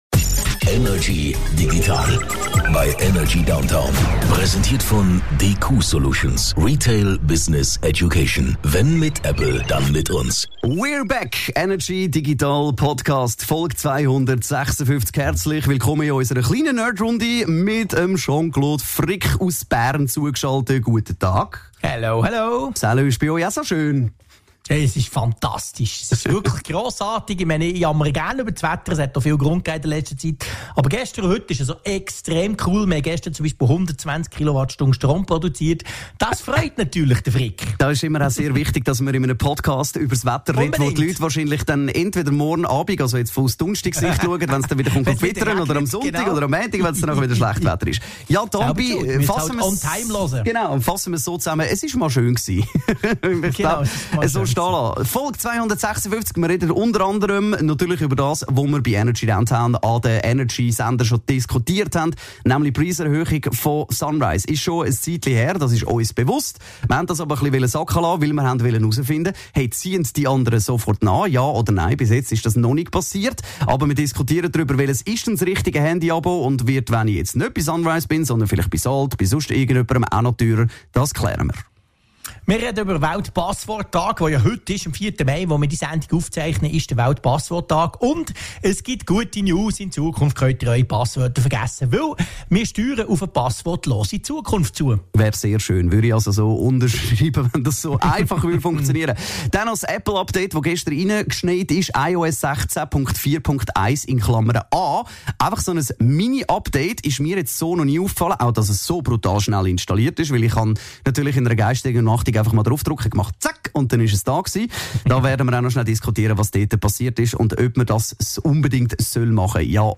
im Energy Studio
aus dem HomeOffice